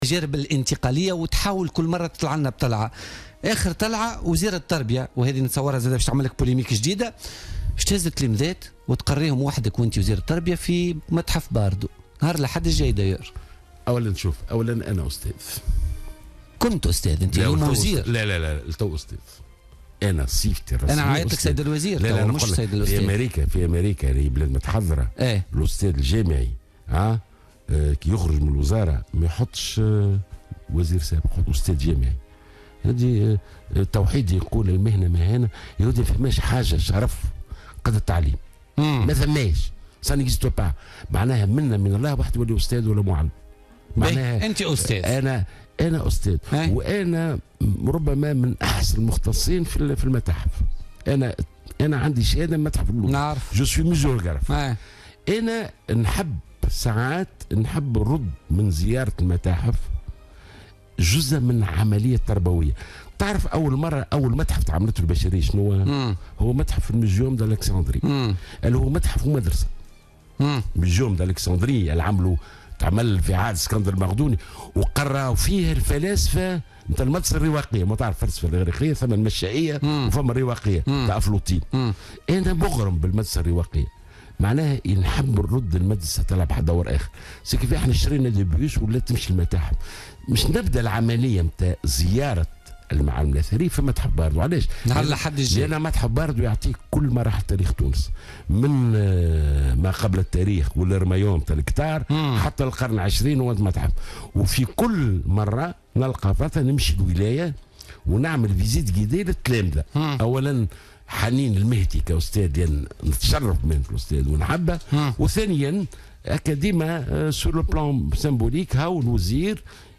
أكد وزير التربية ناجي جلول ضيف بوليتيكا اليوم الجمعة 11 نوفمبر 2016 أنه سيعمل على جعل زيارة المتاحف جزء من العملية التربوية وسيبدأ عملية زيارة المعالم الأثرية من متحف باردو يوم السبت القادم .